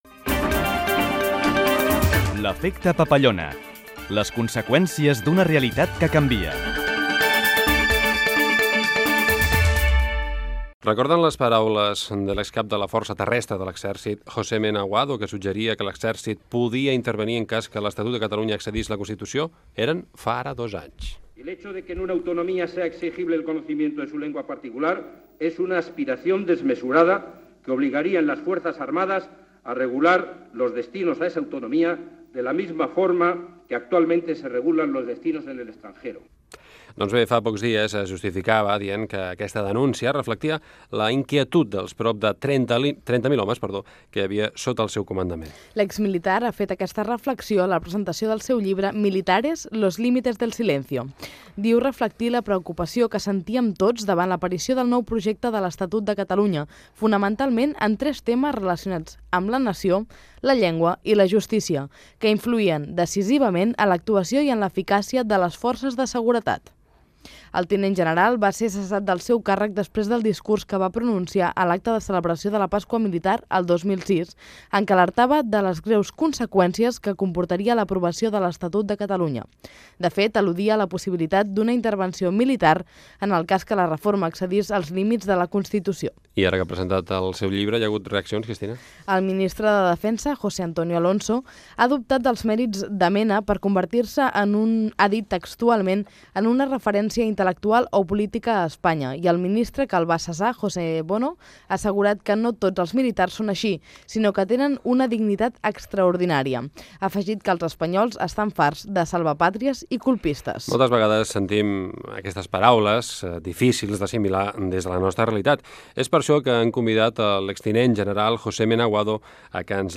Identificació del programa, comentaris a les paraules del Tinent General José Mena sobre l'Estatut de Catalunya i fragment d'una entrevista amb motiu del seu llibre "Militares los límites del silencio"
Informatiu
FM